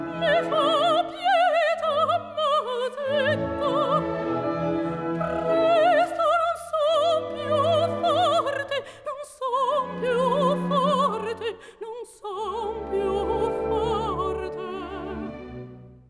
opera_fem2.wav